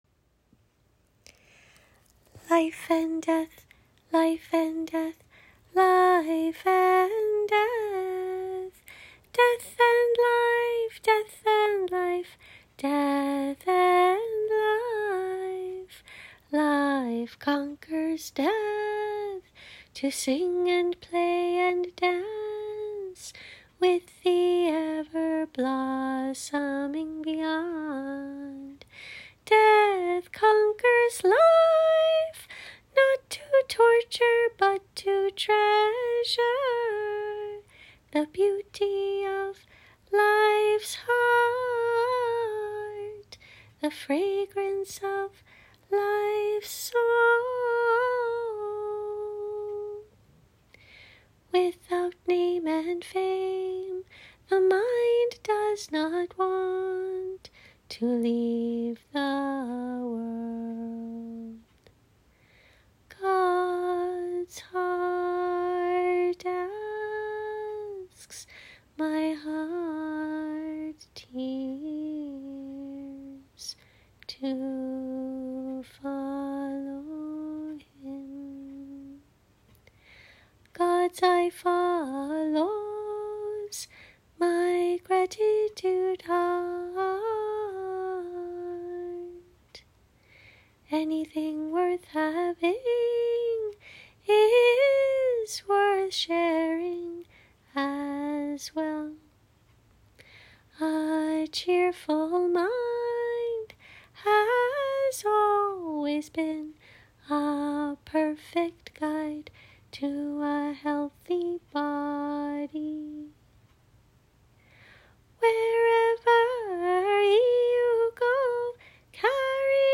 A practice recording of all songs in this set sung one-time each